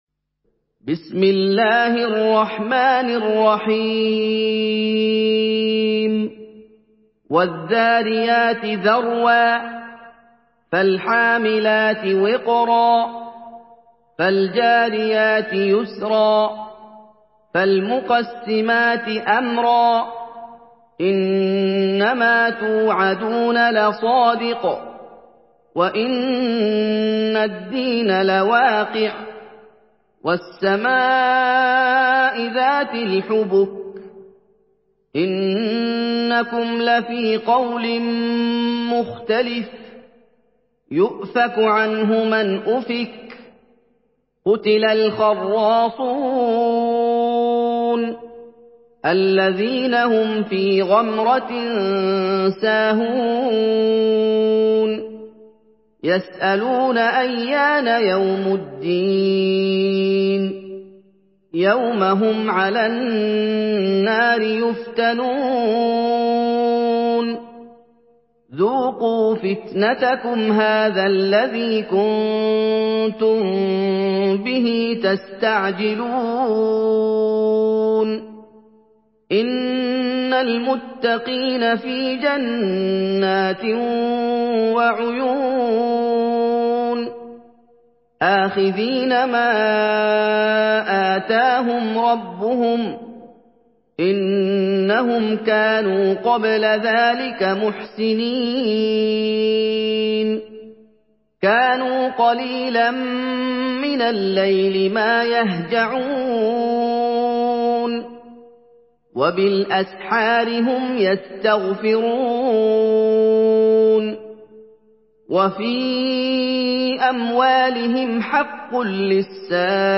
Surah Ad-Dariyat MP3 by Muhammad Ayoub in Hafs An Asim narration.
Murattal